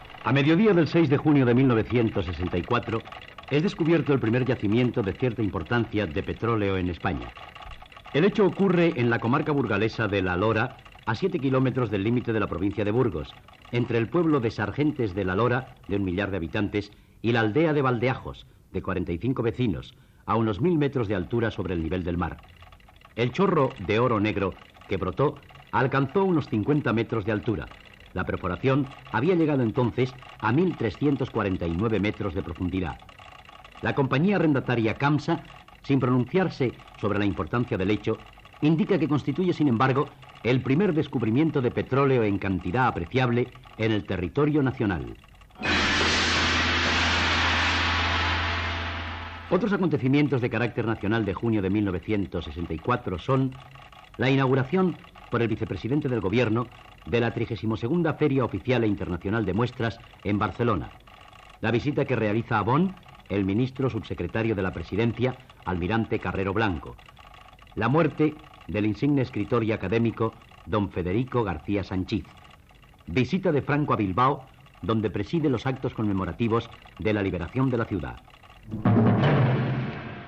Resum informatiu de l'any 1964. Descobriment de petroli a la comarca de La Lora (Burgos) el mes de juny i altres fets significatiu d'aquell mes.
Informatiu